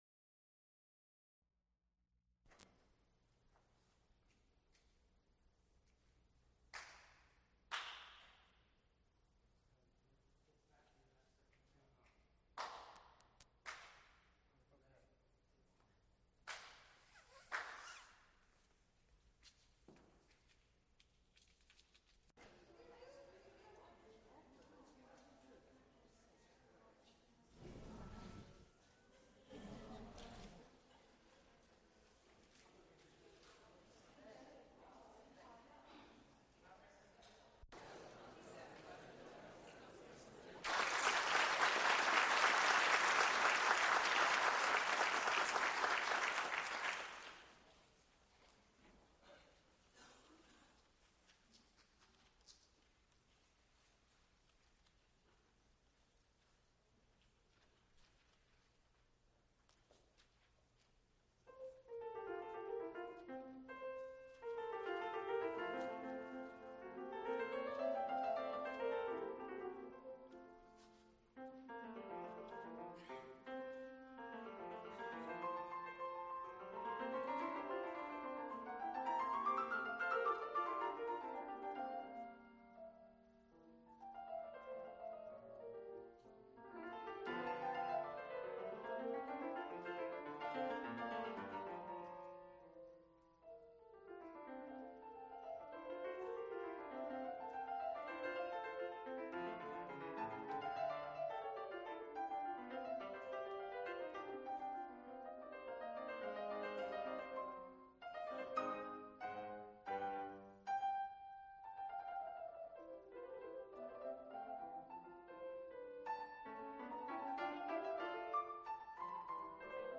Recorded live October 2, 1984, Frick Fine Arts Auditorium, University of Pittsburgh.
clarinet
piano
Clarinet and piano music